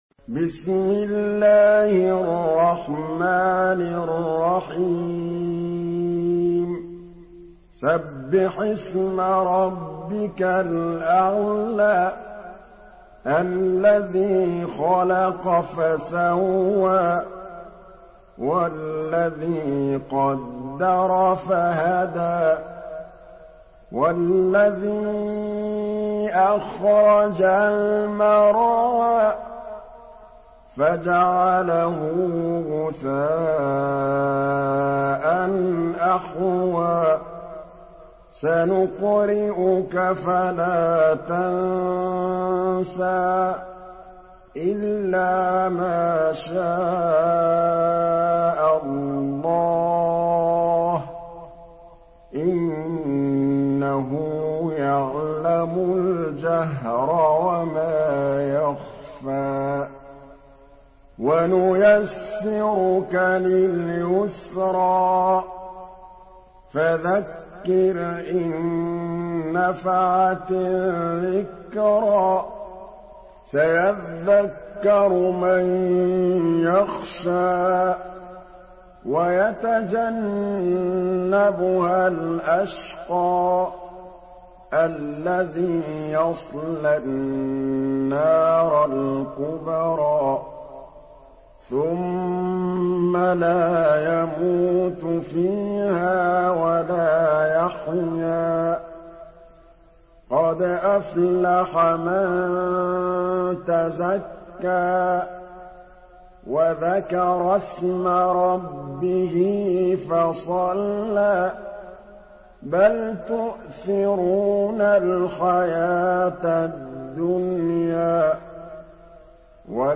سورة الأعلى MP3 بصوت محمد محمود الطبلاوي برواية حفص
مرتل